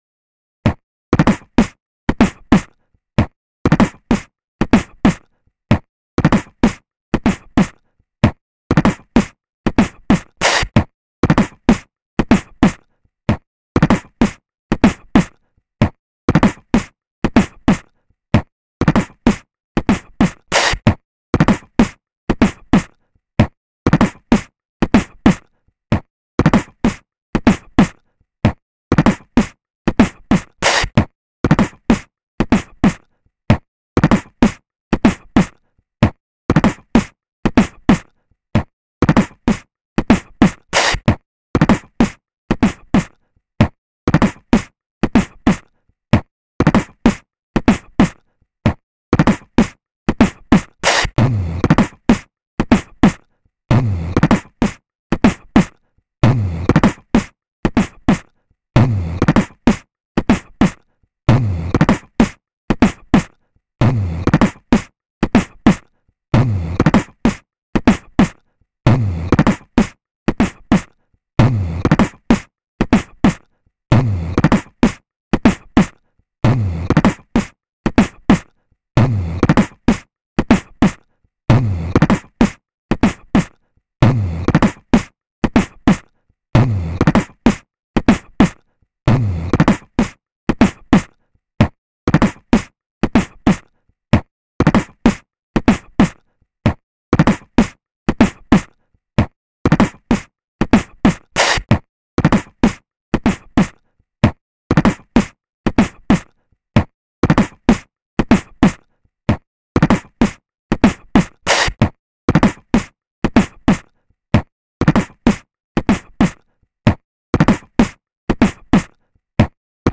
Beatbox loop #03
Włącz, aby poćwiczyć beatbox z bitem do pomocy. Bit jest na tyle prosty, że możesz dodać tam wiele swoich dźwięków, do czego właśnie zachęcam. Tak jak poprzednie, został podzielony na sekcję z bitem i drugą część z samym basem.
loop 3 (4/4, 95bpm, 5:45min, 5.23mb)